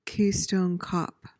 PRONUNCIATION:
(KEE-stohn kop)